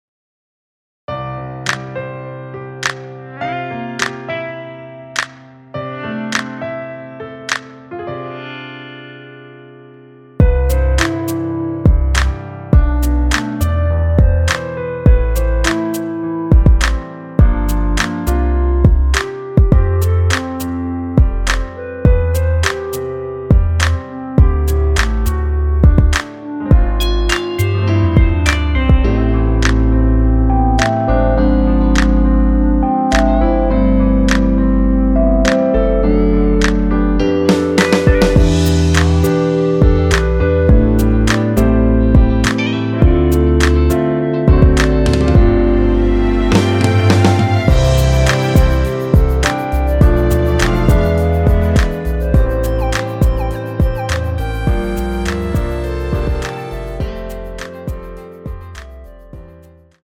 랩없이 혼자 부를수 있는 버전의 MR입니다.
원키 멜로디 포함된 랩없는 버전 MR입니다.
앞부분30초, 뒷부분30초씩 편집해서 올려 드리고 있습니다.
중간에 음이 끈어지고 다시 나오는 이유는